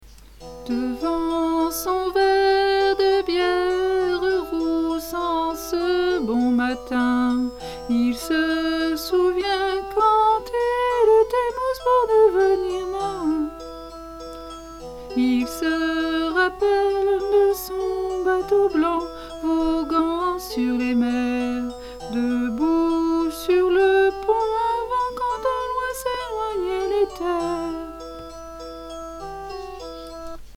belle musique et triste à la fois, le thème est :bravo2:
Le titre exact doit être "Three Little Boats Went Out to Sea", gigue de l'île de Man.